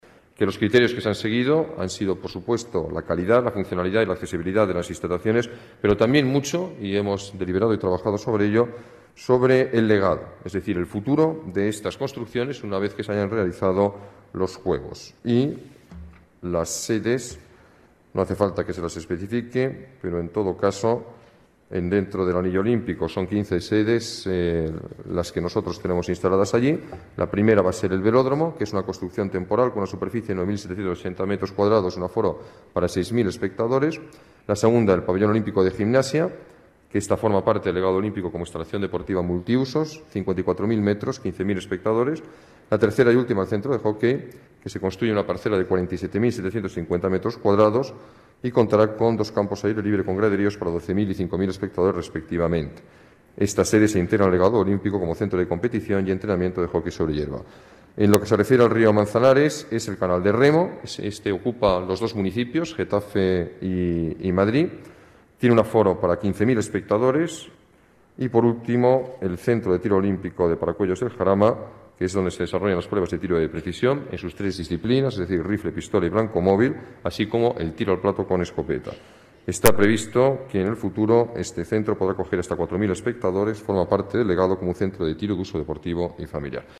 Nueva ventana:Declaraciones del alcalde sobre los ganadores del concurso de sedes olímpicas